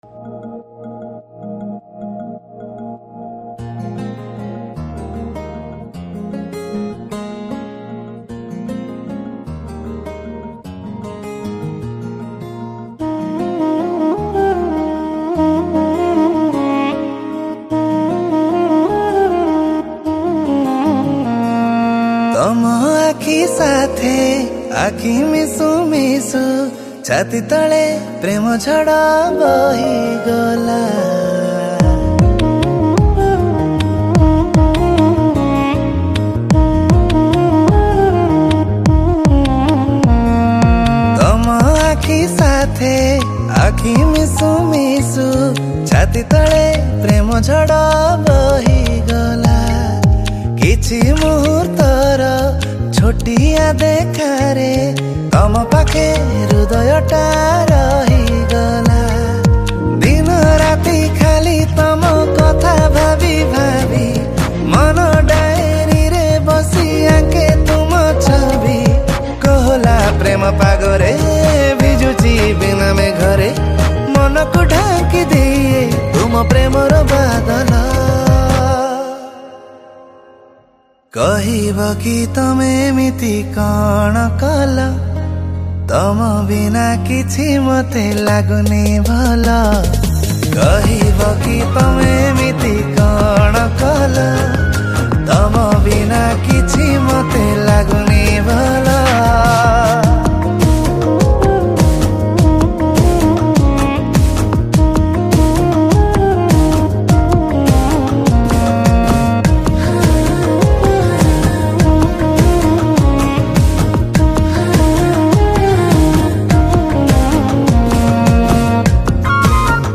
Backing vocals